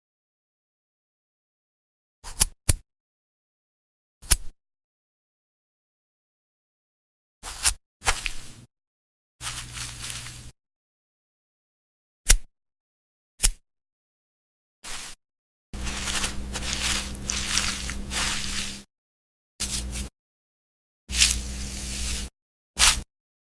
ASMR Cleaning Video 📹 sound effects free download